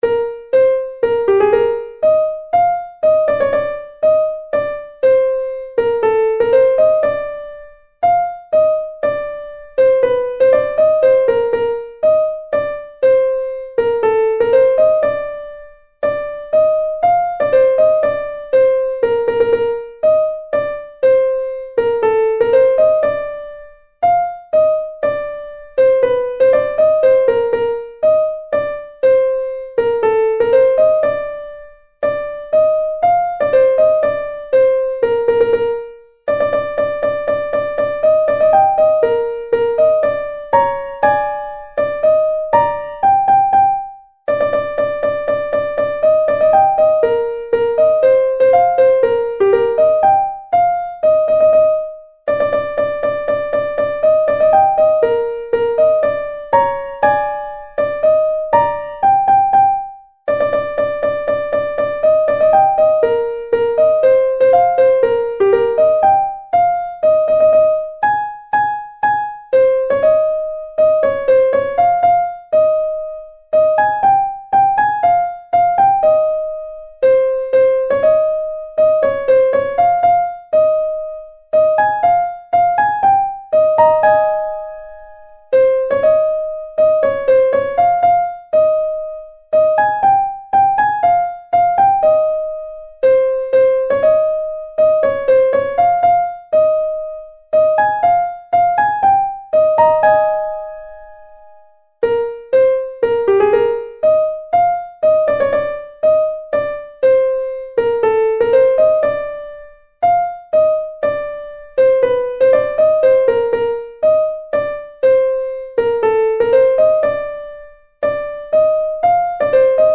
Version originale
Méli-mélo Cote partithèque CdL : H10042 Fantaisie humoristique pour chœur d’hommes en forme de pas redoublé.